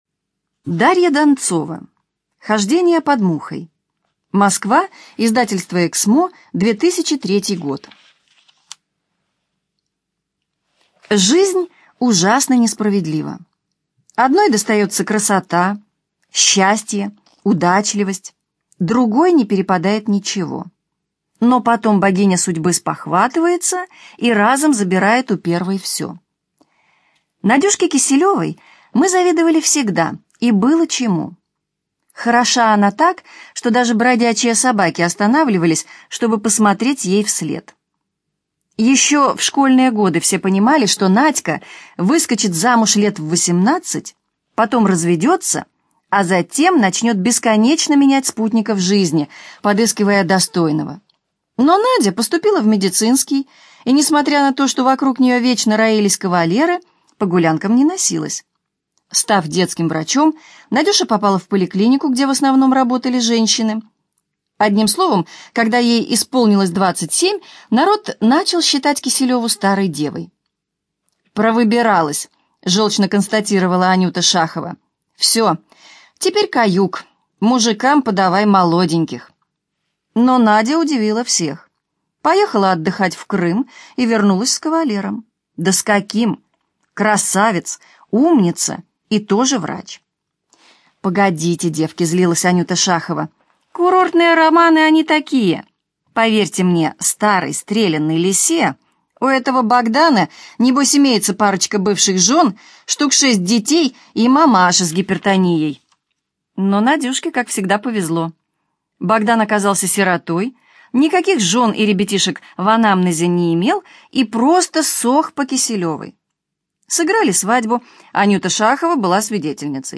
Аудиокнига Хождение под мухой - купить, скачать и слушать онлайн | КнигоПоиск